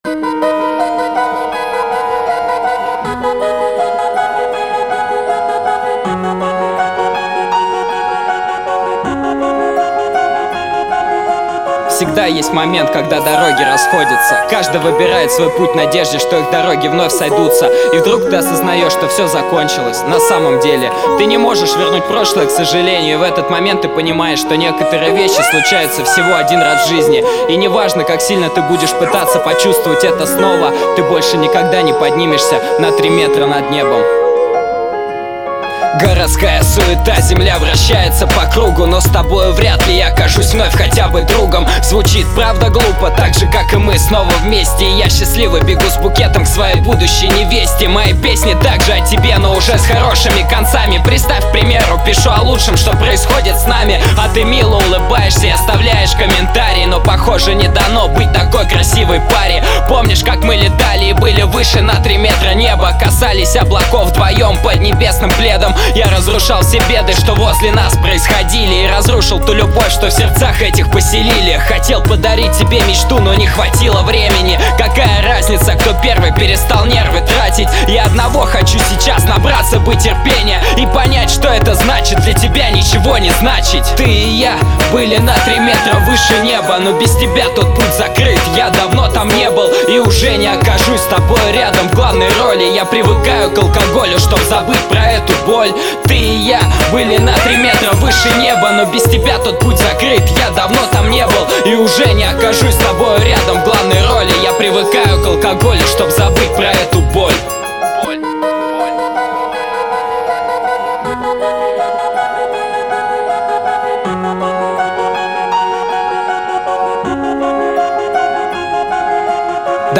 Главная » Mp3 музыка » RAP, R&B